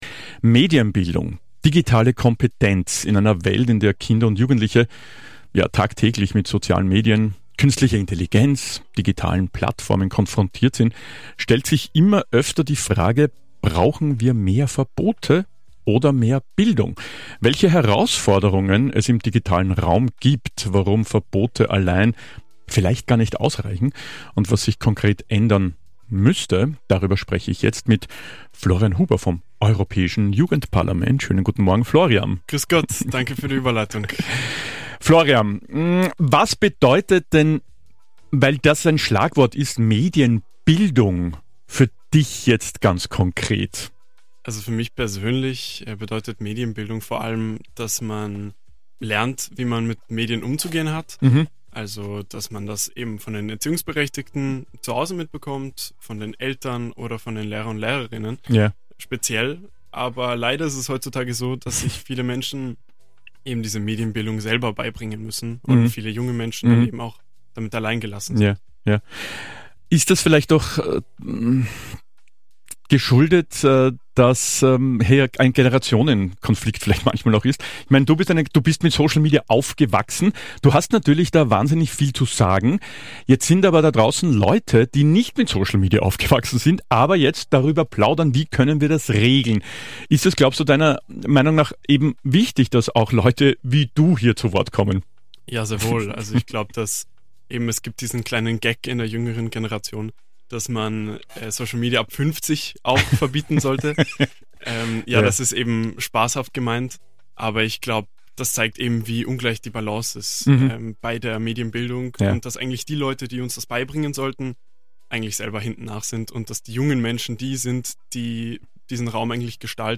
Superfly Featured | Im Gespräch